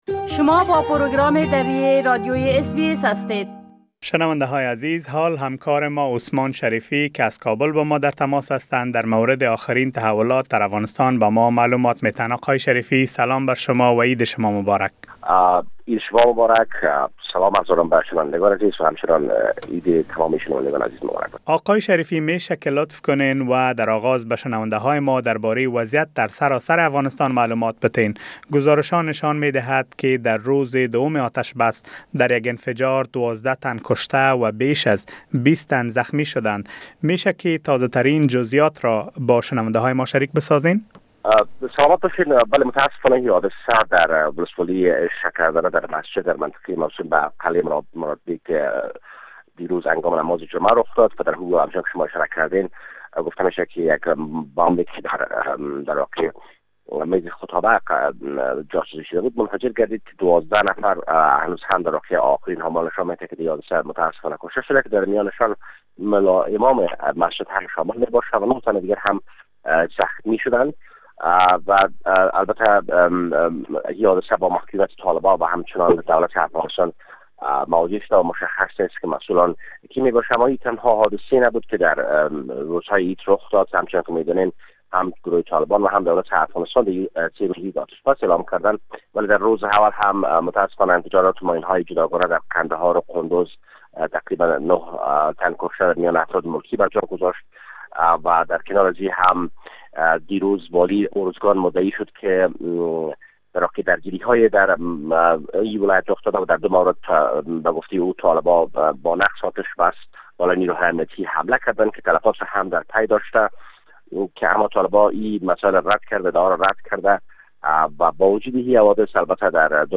گزارش كامل خبرنگار ما در كابل، به شمول اوضاع امنيتى و تحولات مهم ديگر در افغانستان را در اينجا شنيده ميتوانيد.